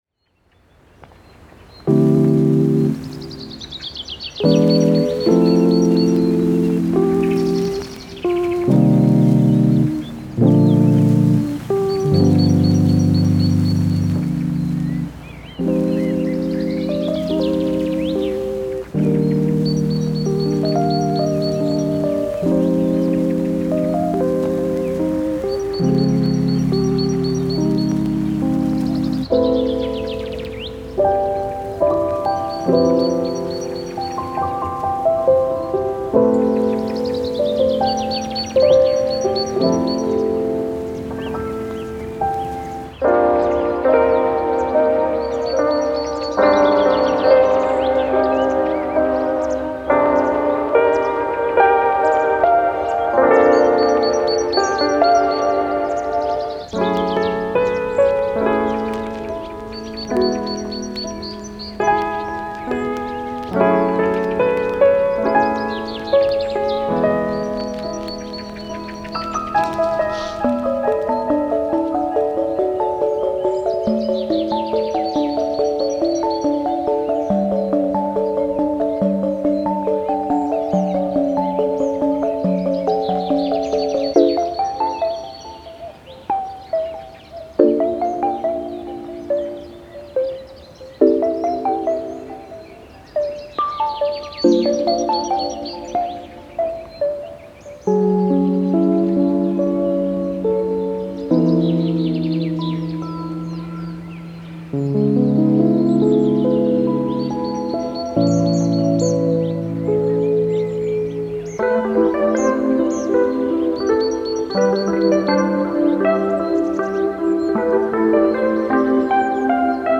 Genre:Ambient
028 E-piano
033 Piano
026 Synths